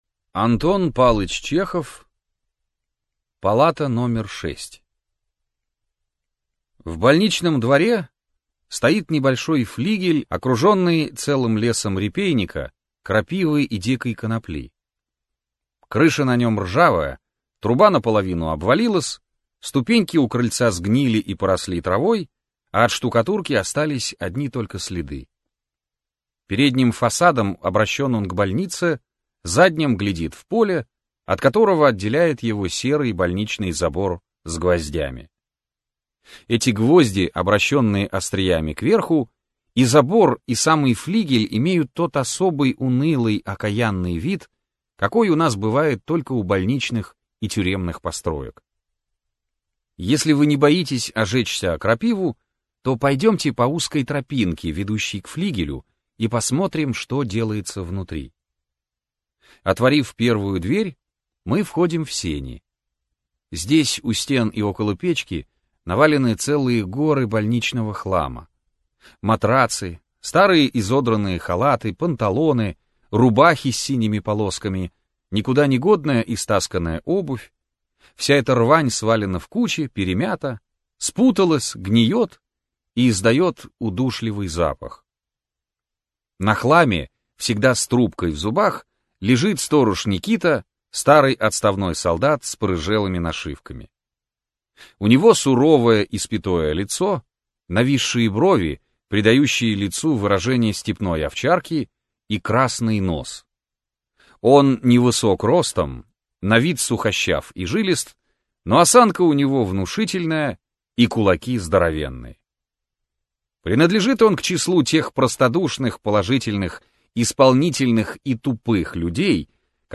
Аудиокнига Палата № 6 - купить, скачать и слушать онлайн | КнигоПоиск